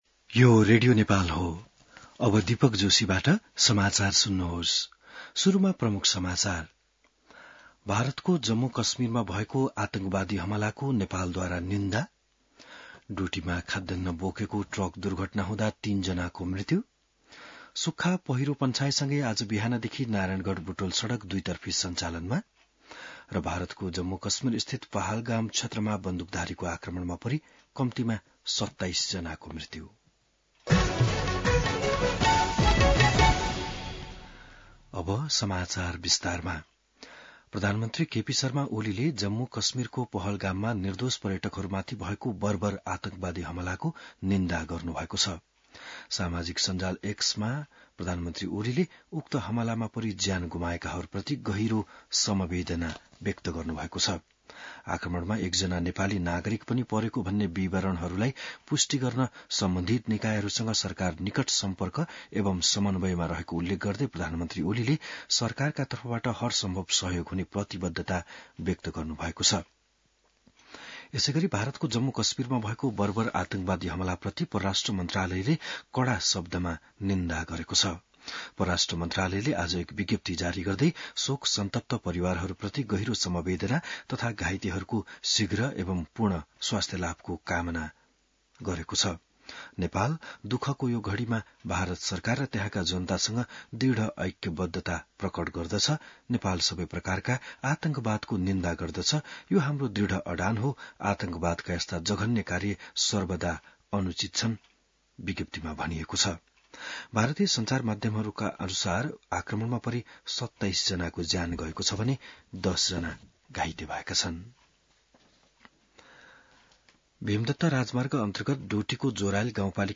बिहान ९ बजेको नेपाली समाचार : १० वैशाख , २०८२